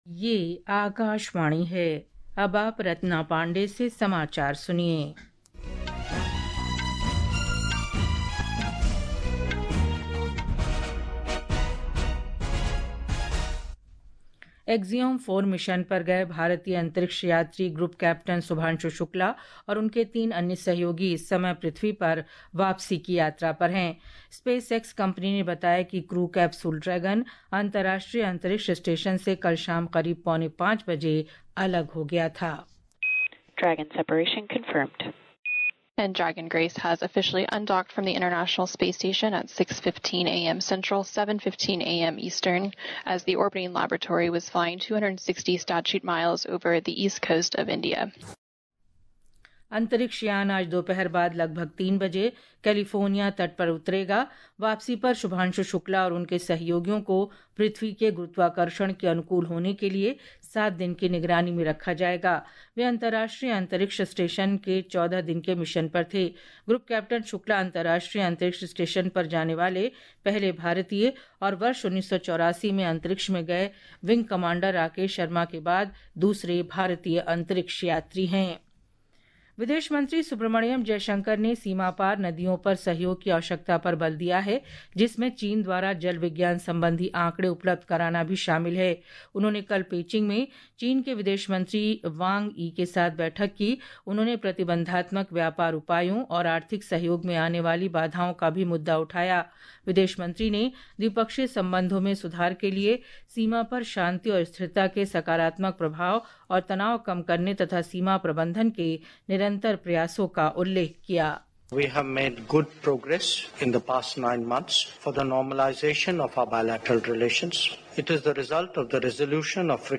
प्रति घंटा समाचार